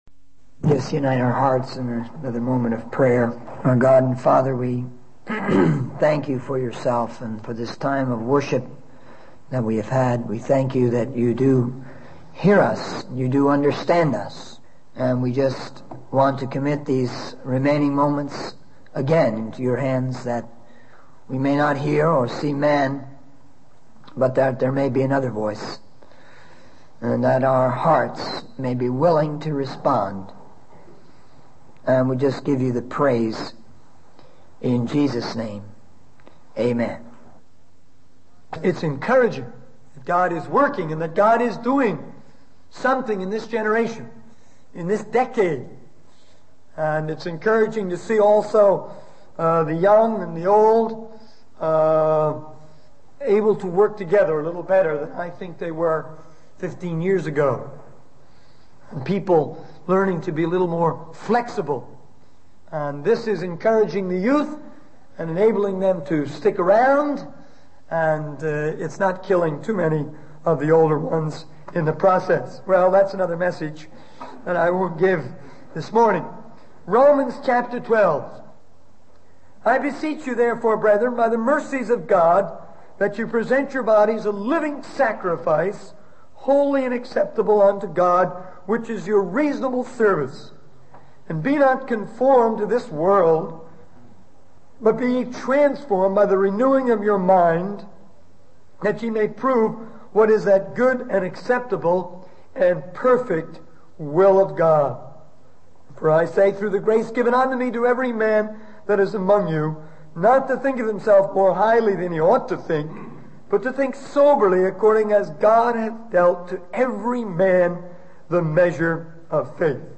In this sermon, the speaker addresses the universal problem of sin and its consequences.